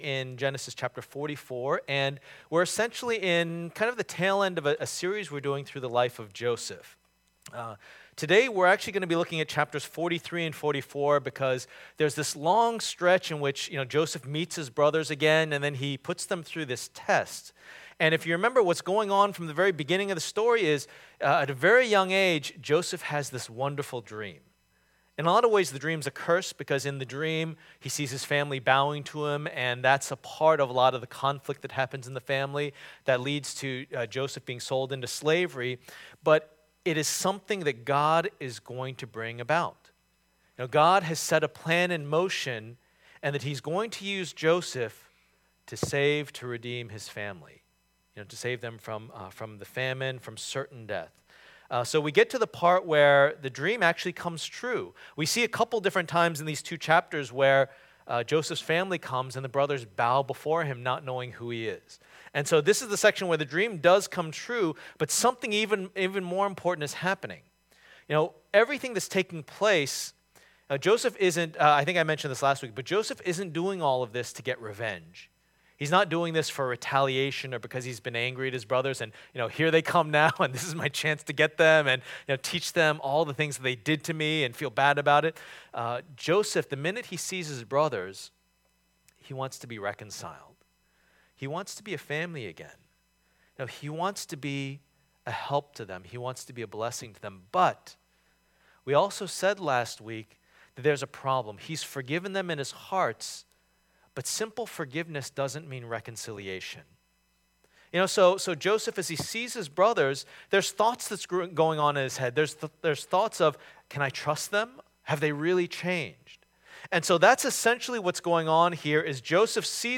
Passage: Genesis 44:1-17 Service Type: Lord's Day